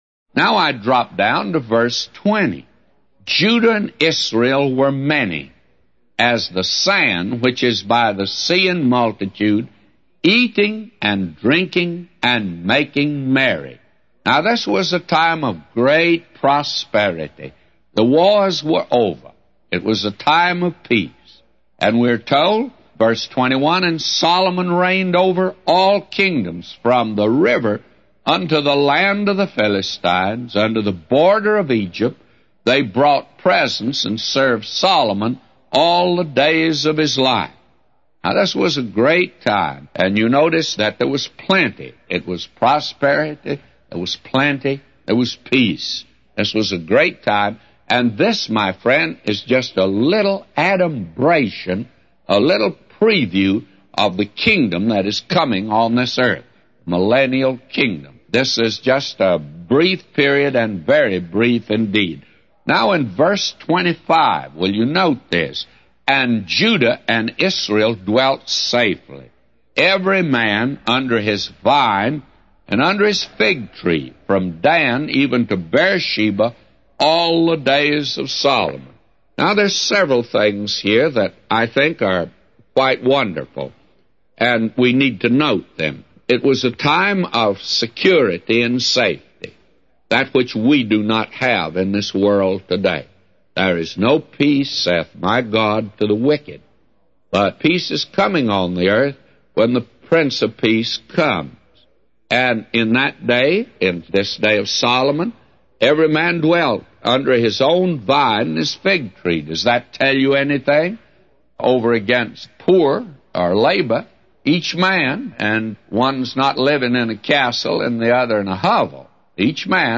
A Commentary By J Vernon MCgee For 1 Kings 4:20-999